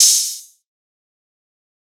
Open Hat [Ronny J Produced The Track].wav